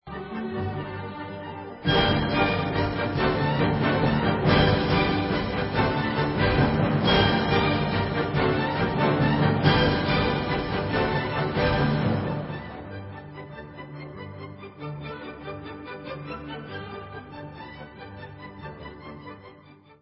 A dur (Allegro vivace) /Skočná